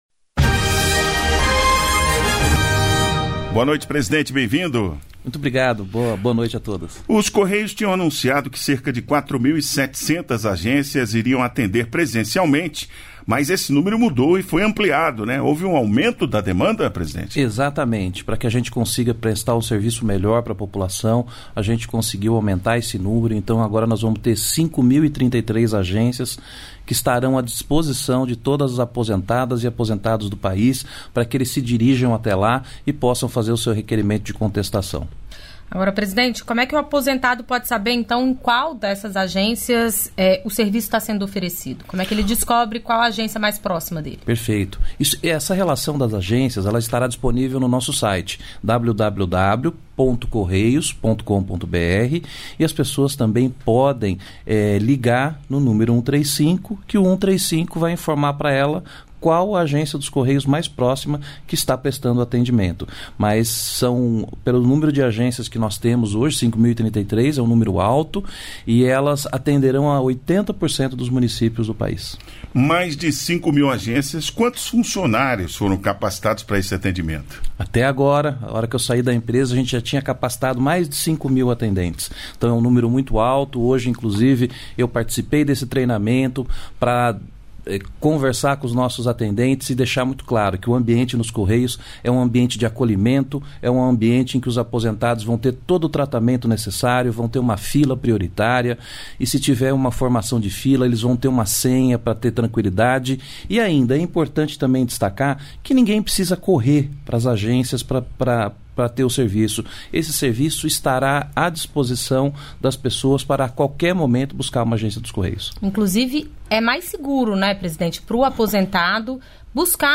Entrevistas da Voz